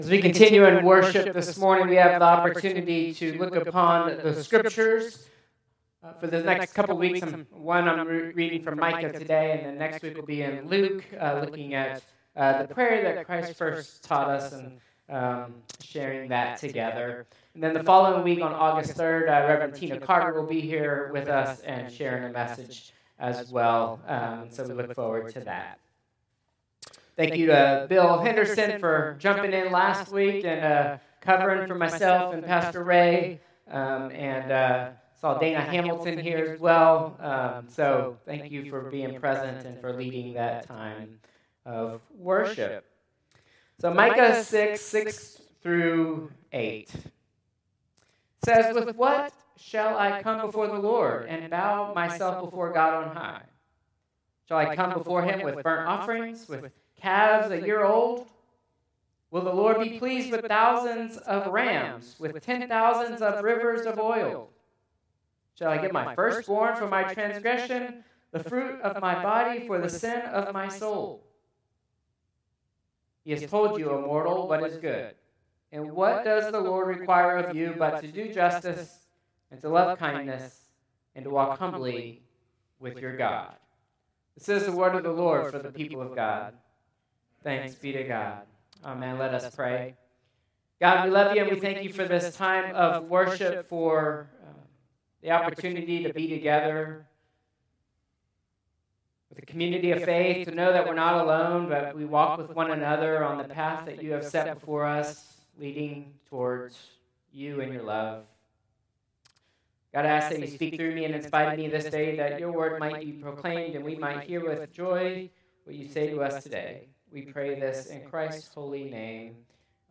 Traditional Service 7/20/2025